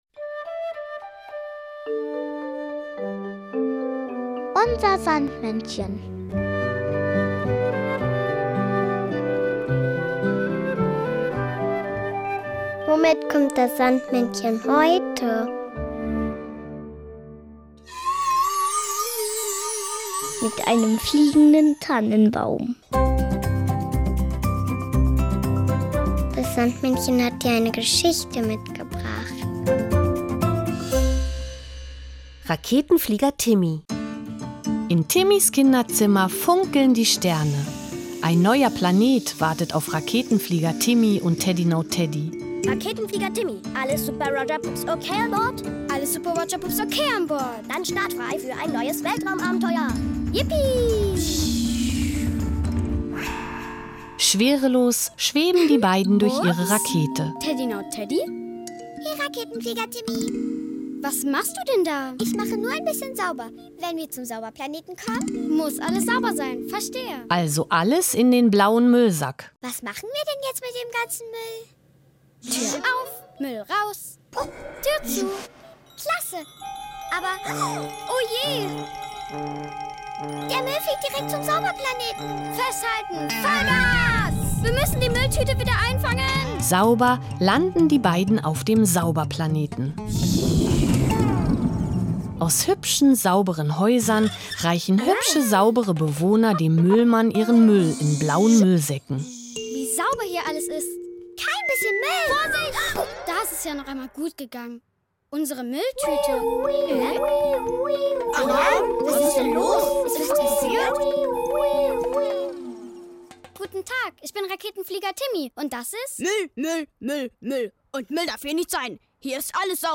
Geschichte mitgebracht, sondern auch noch das Weihnachtslied "Die